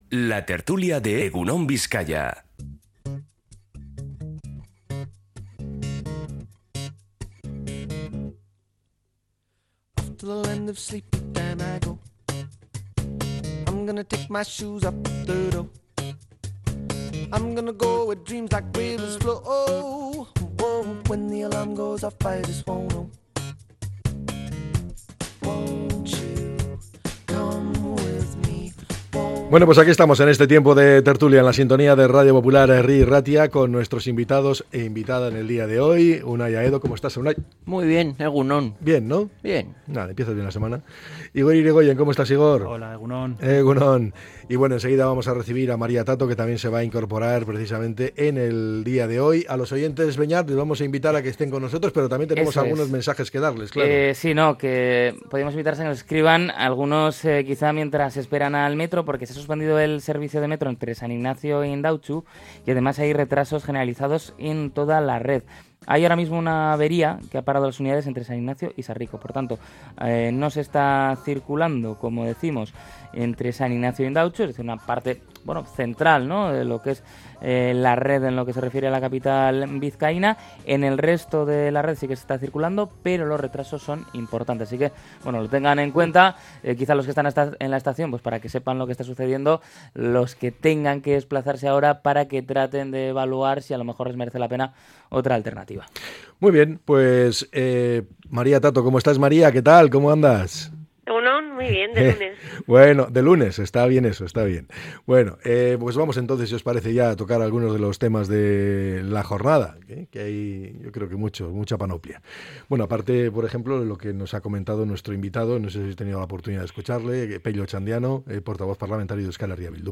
La tertulia 07-10-25.